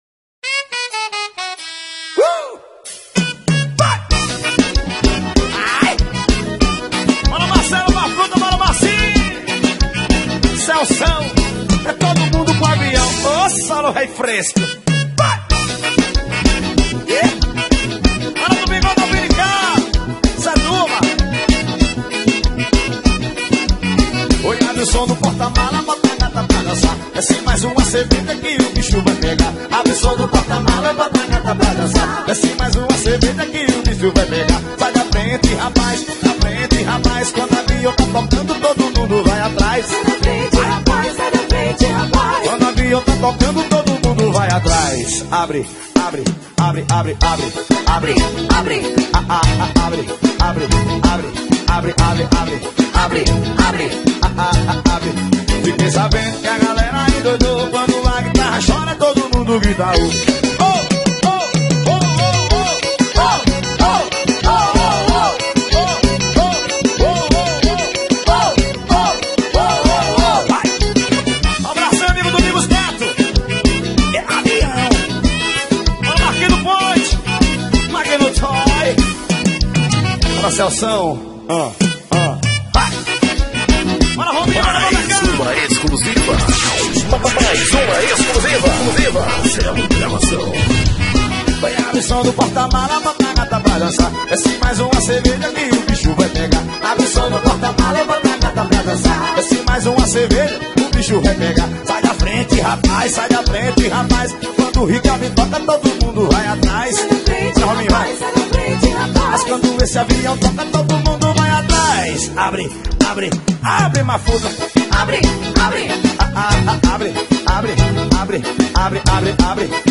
2024-12-30 10:43:21 Gênero: Forró Views